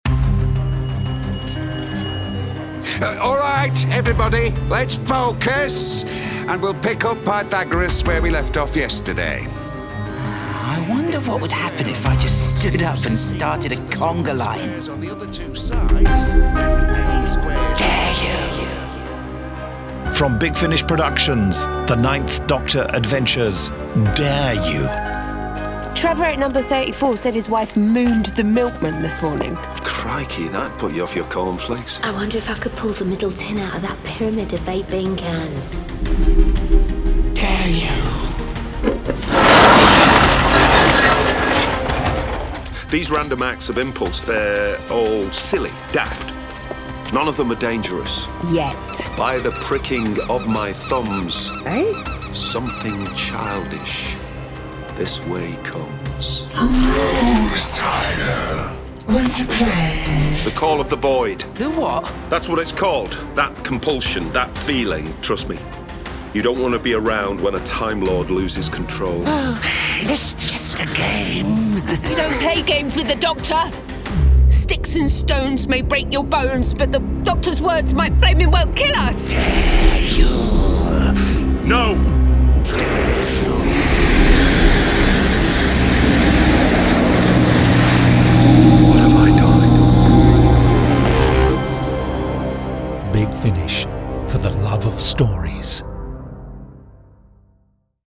Starring Christopher Eccleston Billie Piper